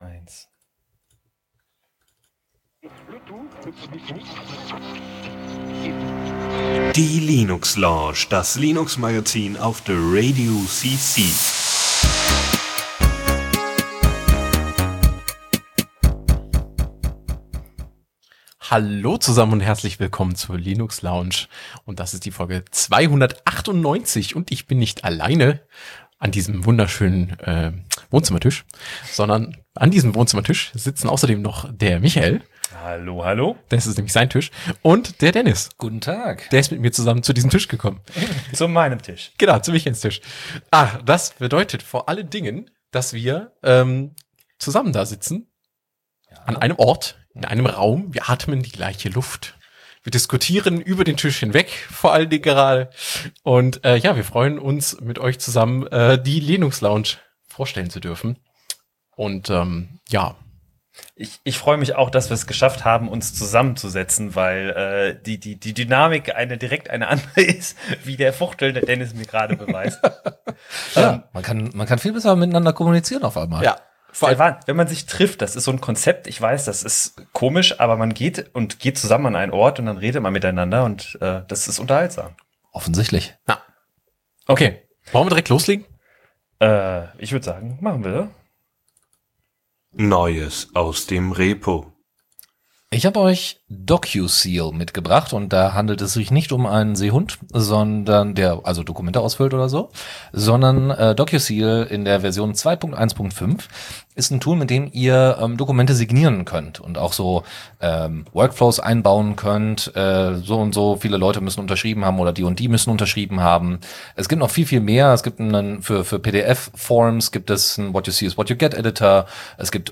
Euer Creative Commons Radio